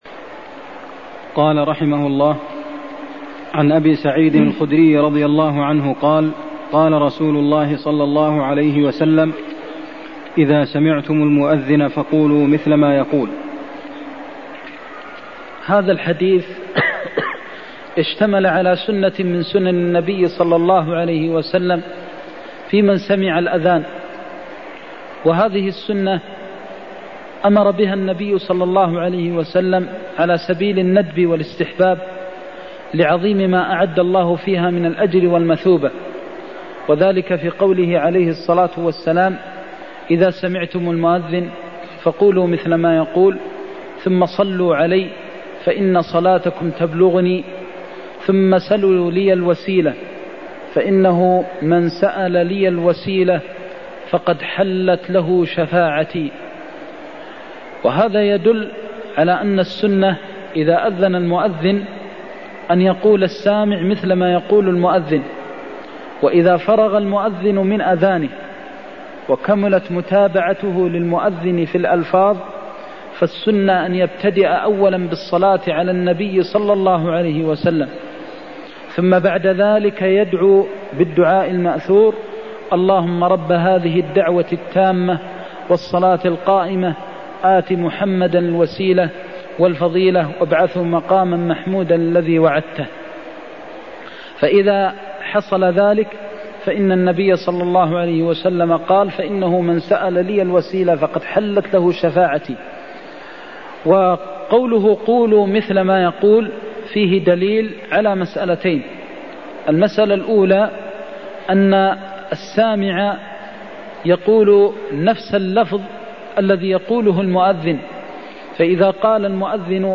المكان: المسجد النبوي الشيخ: فضيلة الشيخ د. محمد بن محمد المختار فضيلة الشيخ د. محمد بن محمد المختار إذا سمعتم المؤذن فقولوا مثل ما يقول (63)